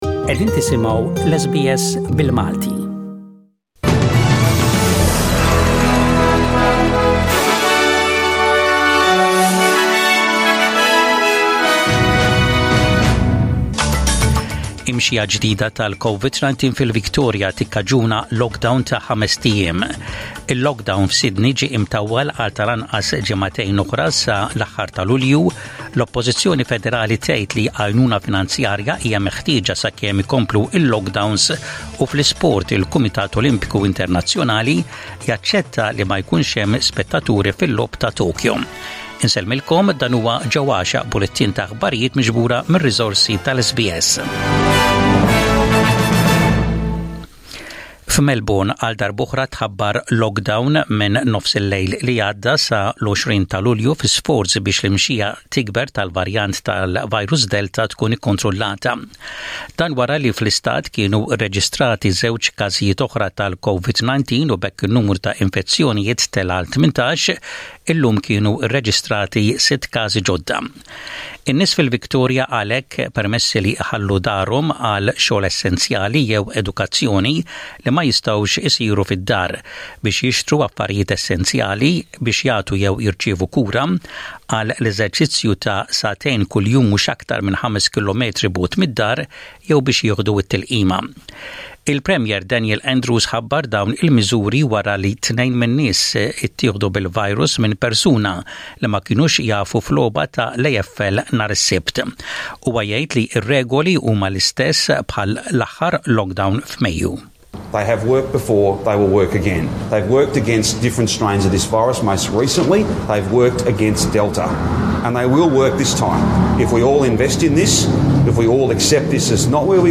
SBS Radio | Maltese News: 16/07/21